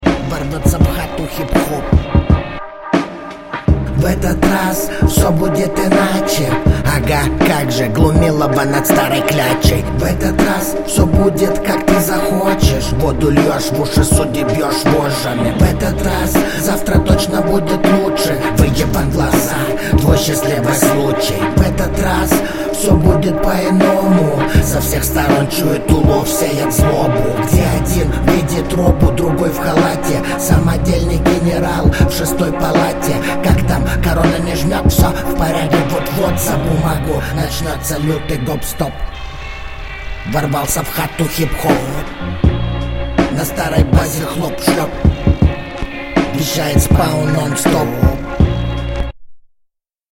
Слишком приглушенный и тихий стиль, не звучит ярко